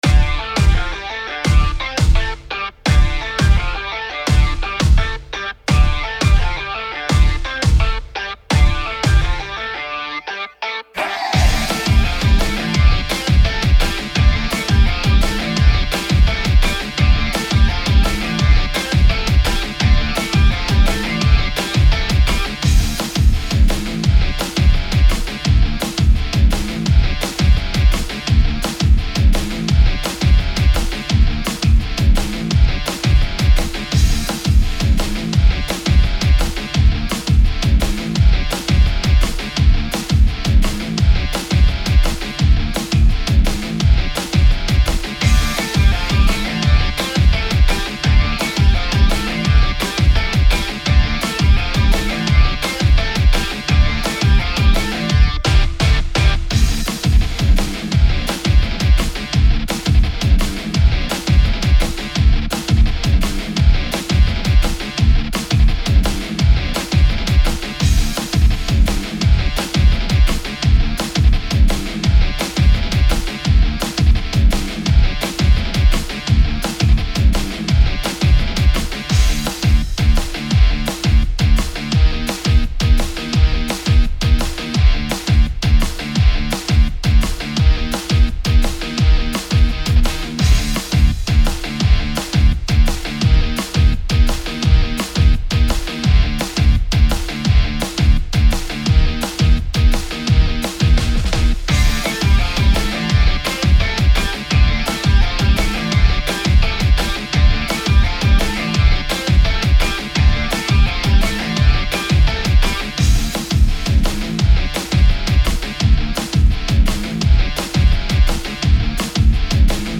打ち込みエレキギターが火を吹くかっこいいナンバー。